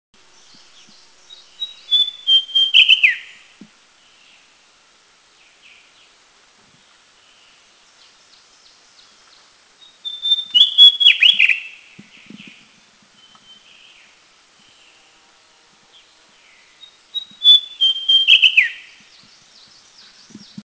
Grey-bellied Tesia
song
Tesia cyaniventer
Grey-belliedTesia.mp3